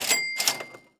AudioClip_Purchase-Cha-Ching.wav